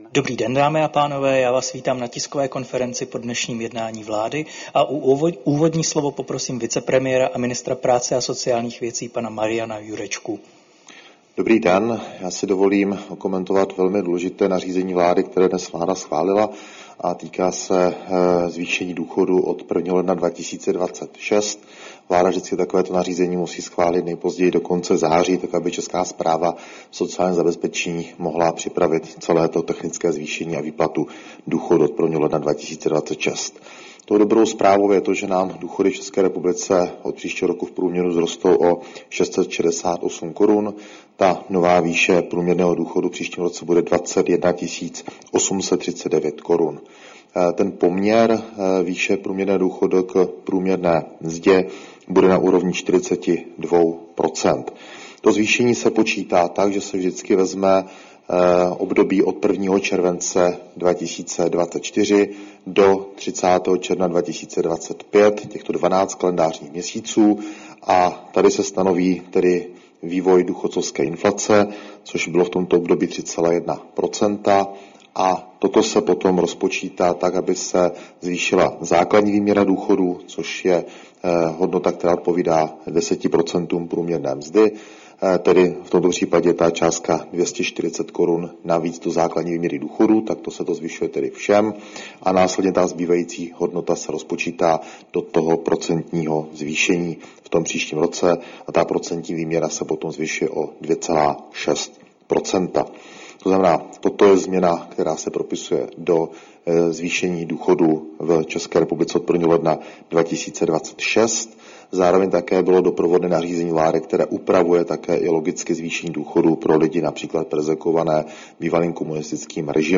Tisková konference po jednání vlády, 24. září 2025